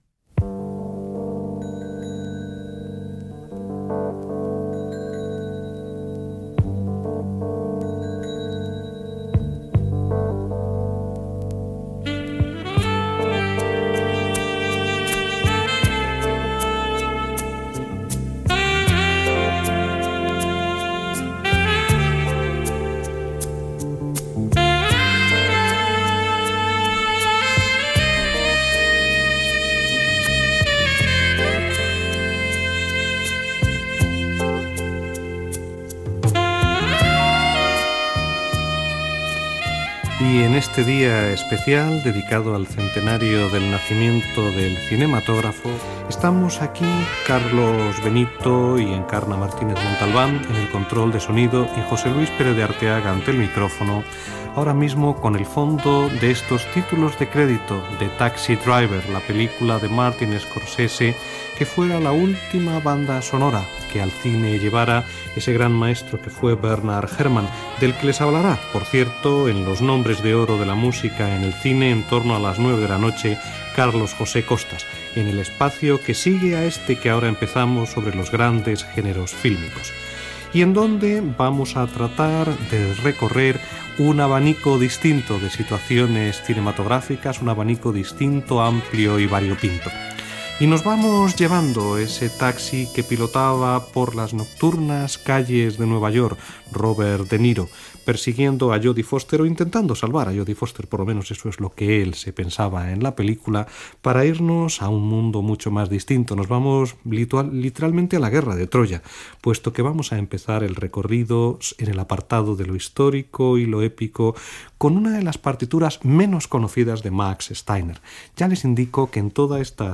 Equip del programa, presentació del tema musical que sona i espai dedicat als gèneres de les bandes sonores del cinema amb motiu del centenari del naixement del cinematògraf Gènere radiofònic Musical